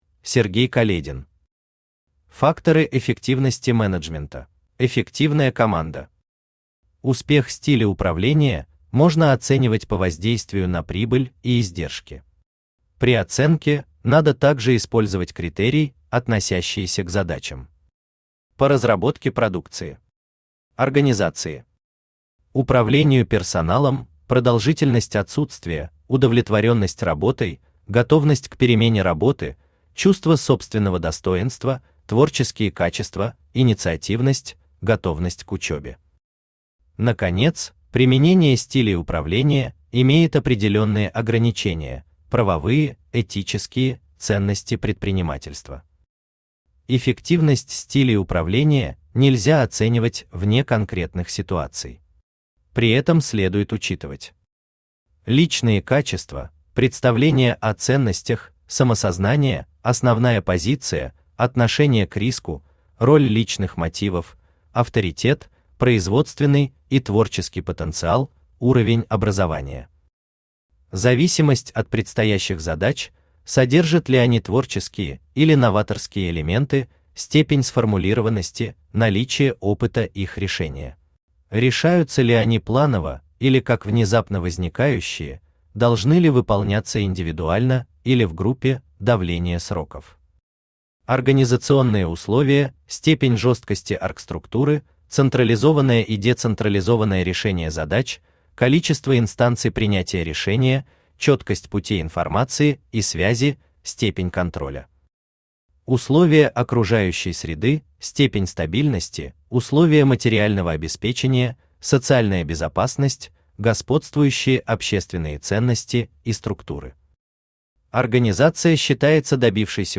Аудиокнига Факторы эффективности менеджмента | Библиотека аудиокниг
Aудиокнига Факторы эффективности менеджмента Автор Сергей Каледин Читает аудиокнигу Авточтец ЛитРес.